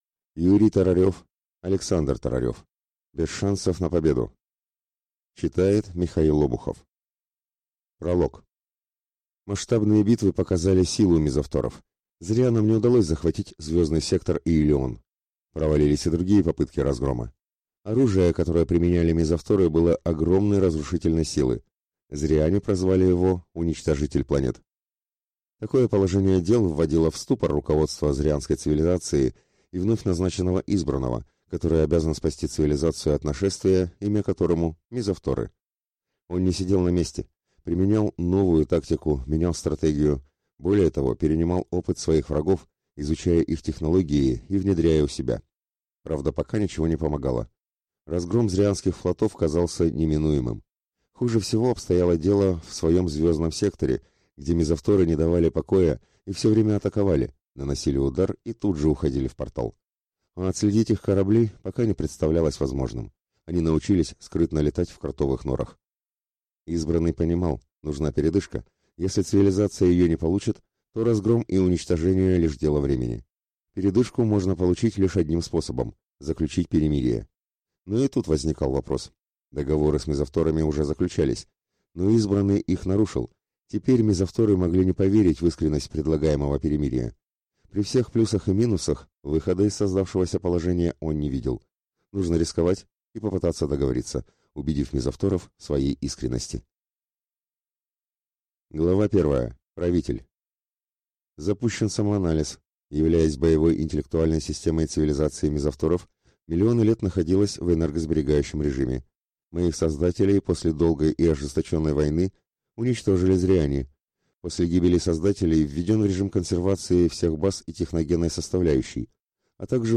Аудиокнига Без шансов на победу | Библиотека аудиокниг